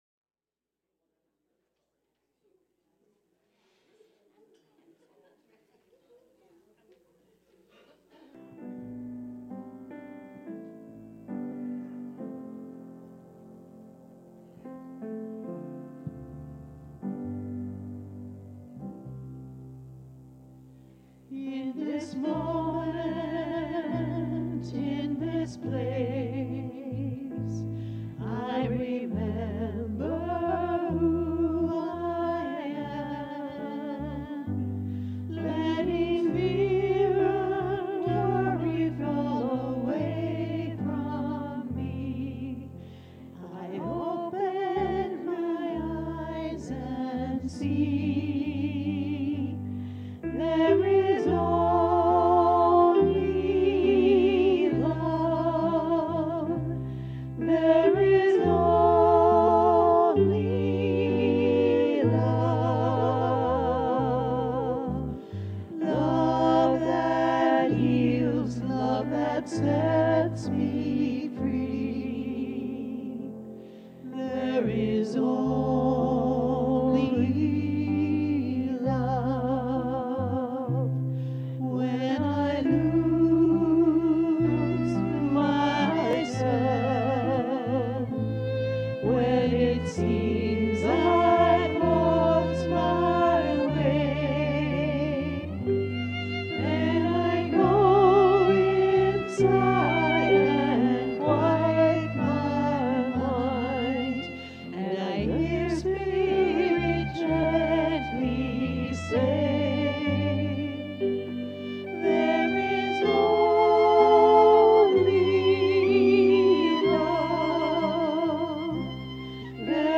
The audio recording below the video clip is an abbreviated version of the service. It includes the Meditation, Message, and Featured Song.